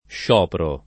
vai all'elenco alfabetico delle voci ingrandisci il carattere 100% rimpicciolisci il carattere stampa invia tramite posta elettronica codividi su Facebook scioperare v.; sciopero [ + š 0 pero ] — ant. scioprare : sciopro [ + š 0 pro ]